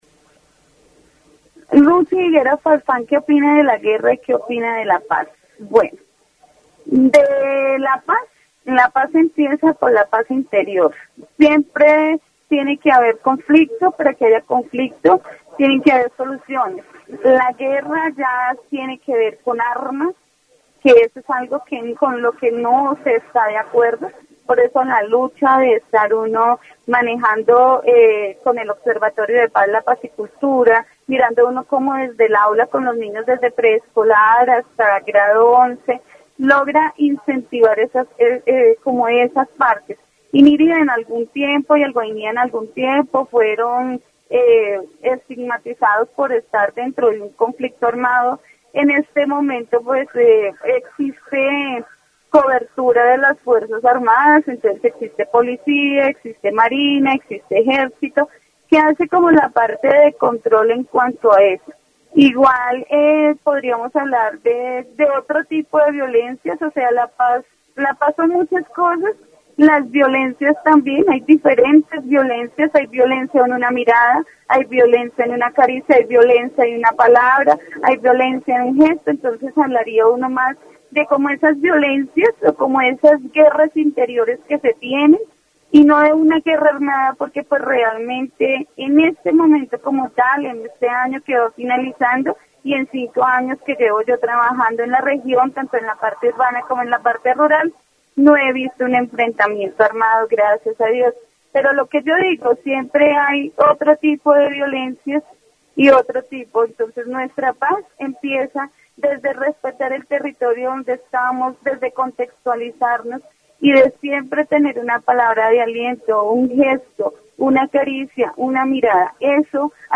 Interview on peace and violence in Guainía, focusing on the importance of inner peace, respect for the territory, and the different types of violence affecting the community.
Entrevista sobre la paz y la violencia en Guainía, con un enfoque en la importancia de la paz interior, el respeto al territorio y los diferentes tipos de violencia que afectan a la comunidad.